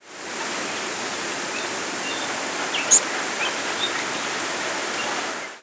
Golden-winged Warbler diurnal flight calls